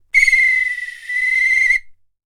Свистки звуки скачать, слушать онлайн ✔в хорошем качестве